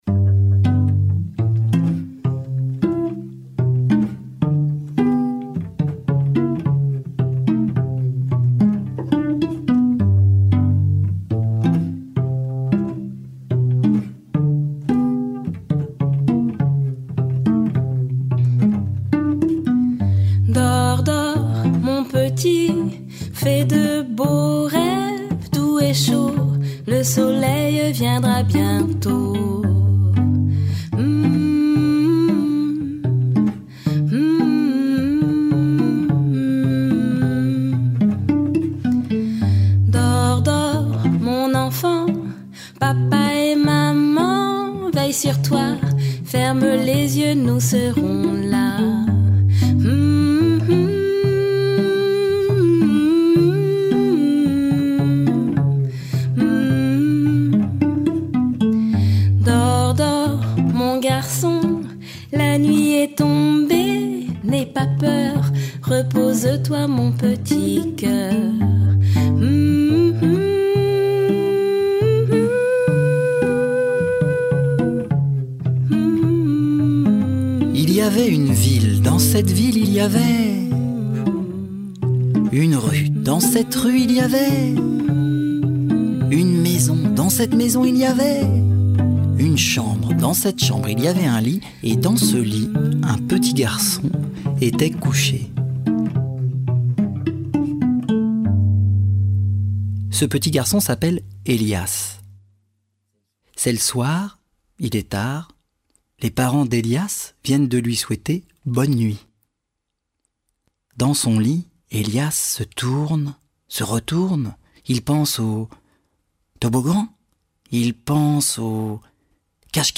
Diffusion distribution ebook et livre audio - Catalogue livres numériques
Mots, chants et musique se répondent et nous emportent dans un monde peuplé d’étranges personnages pour des aventures réjouissantes.